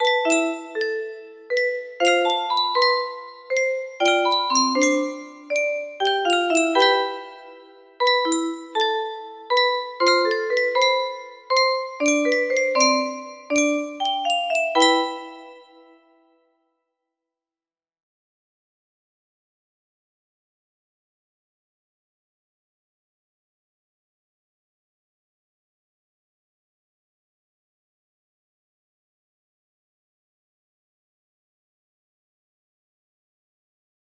Clone of Die 222Gether Lava music box melody